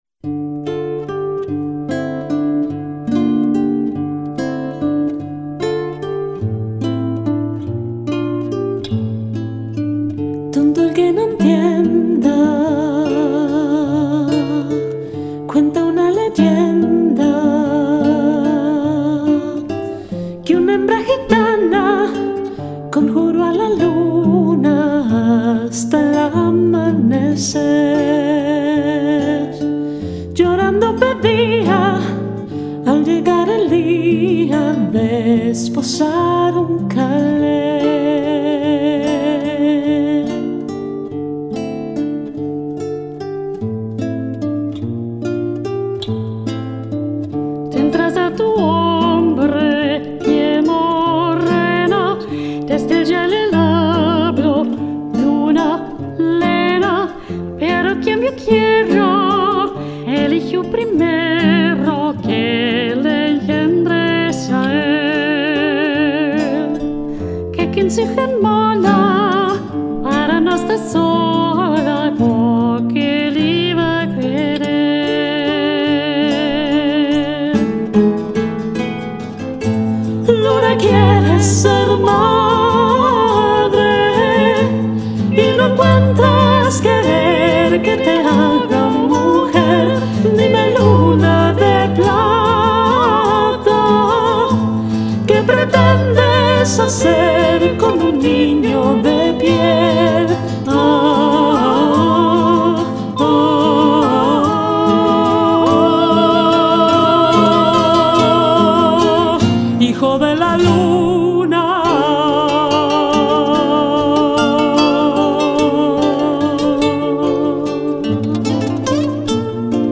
La vocalista soprano
grabó junto a otra vocalista soprano